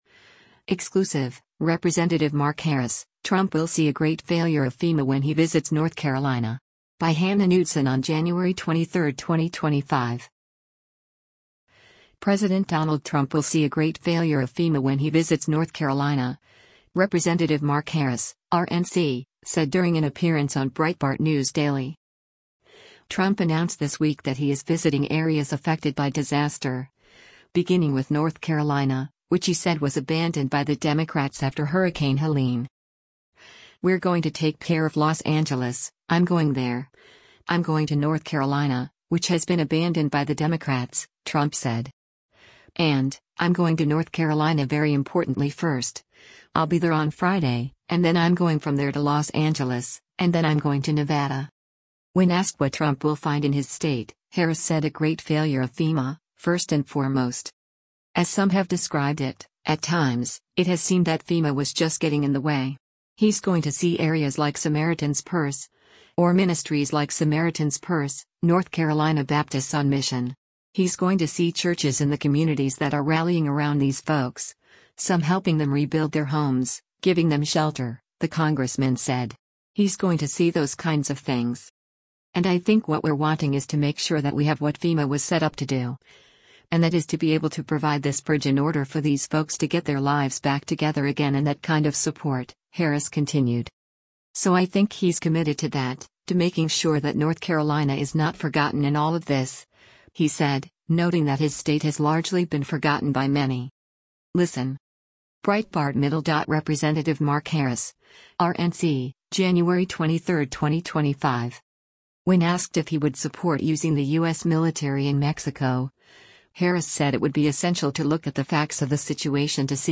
President Donald Trump will see a “great failure of FEMA” when he visits North Carolina, Rep. Mark Harris (R-NC) said during an appearance on Breitbart News Daily.
Breitbart News Daily airs on SiriusXM Patriot 125 from 6:00 a.m. to 9:00 a.m. Eastern.